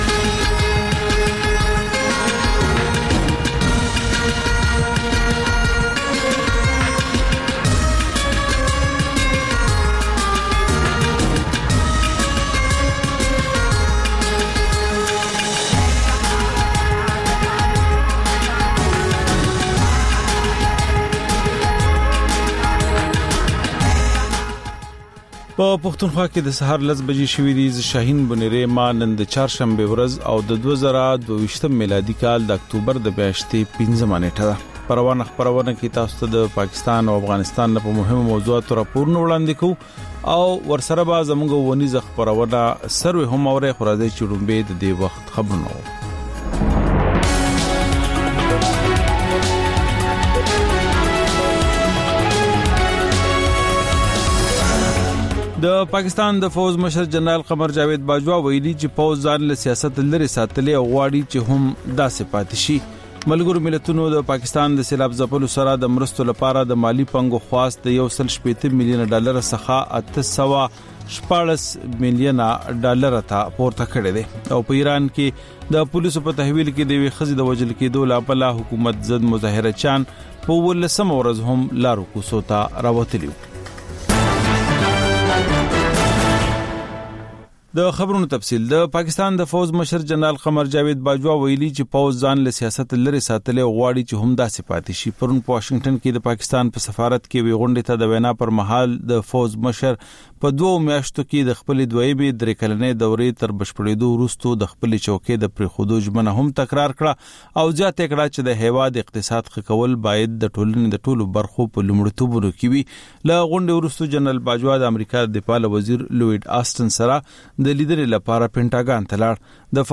په دې خپرونه کې تر خبرونو وروسته بېلا بېل رپورټونه، شننې او تبصرې اورېدای شﺉ.